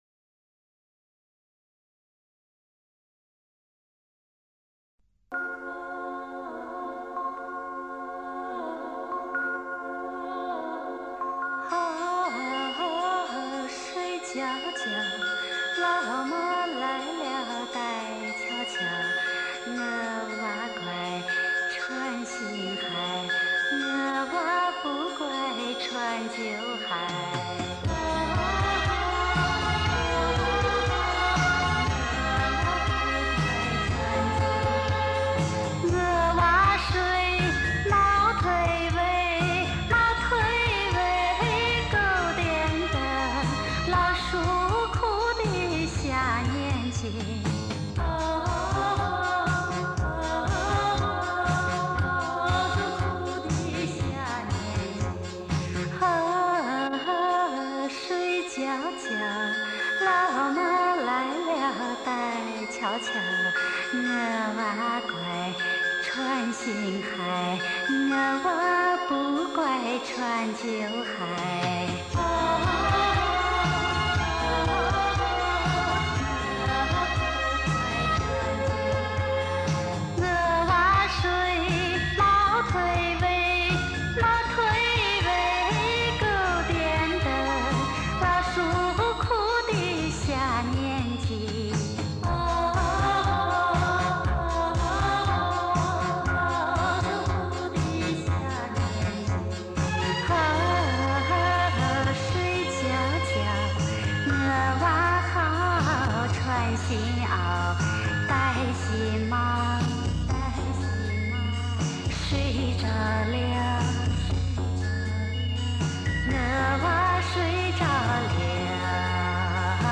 [分享]陕西关中地区的经典摇篮曲《睡觉觉》
这是一首典型的陕西关中地区的民歌“摇篮曲”《睡觉觉》。